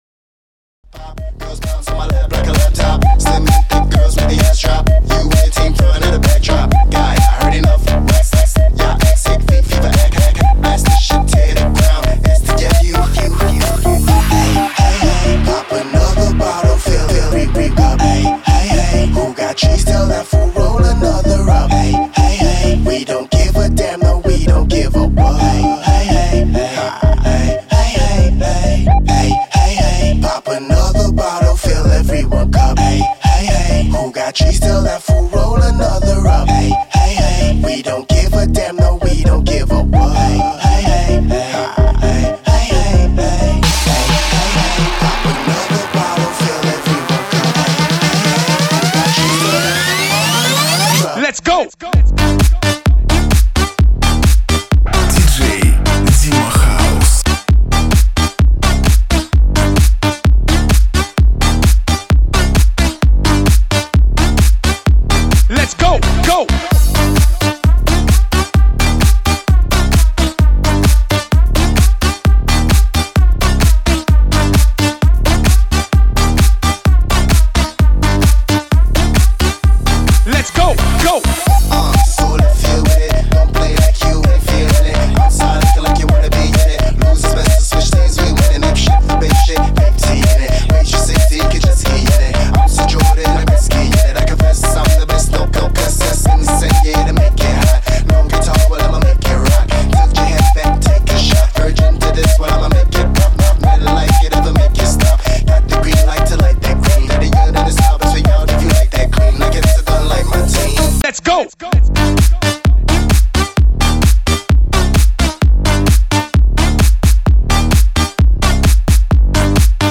Жанр : Клубняк